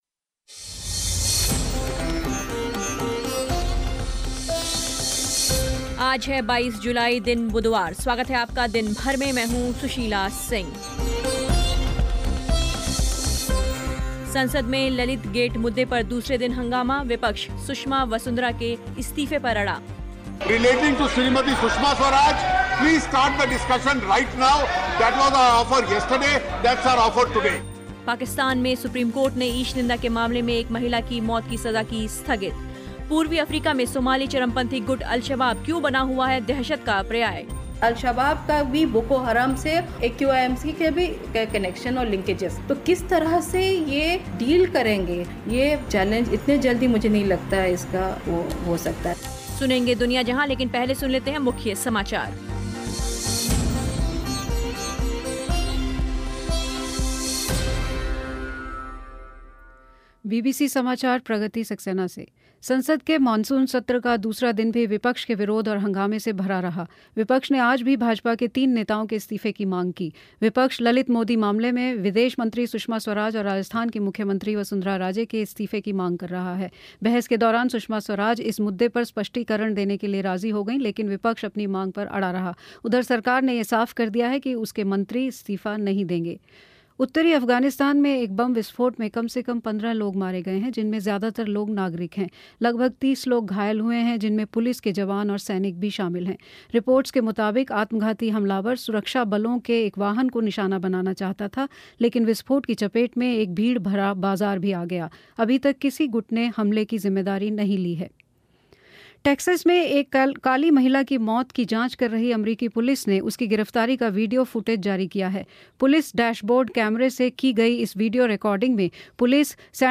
प्रसारण की रिकॉर्डिंग अगले चौबीस घंटों तक बीबीसी हिन्दी डॉट कॉम पर उपलब्ध रहती है. इस कार्यक्रम में विश्व समाचार, विश्लेषण, प्रमुख हस्तियों और विशेषज्ञों से बातचीत, खेल और विशेष साप्ताहिक कार्यक्रम प्रसारित किए जाते हैं.